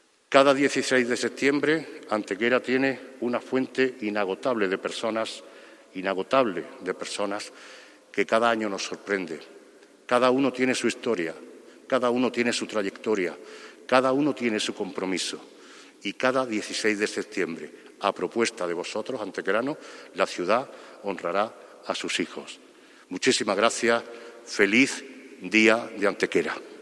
La Real Colegiata de Santa María la Mayor ha acogido en el mediodía de hoy jueves 16 de septiembre el acto institucional de entrega de distinciones de honor del Ayuntamiento de Antequera, iniciativa que se desarrolla coincidiendo con el mismo día en que se cumplen 611 años de la conquista de Antequera por parte del Infante Don Fernando y la proclamación de Santa Eufemia como Patrona de la ciudad.
Cortes de voz